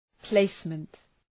Προφορά
{‘pleısmənt}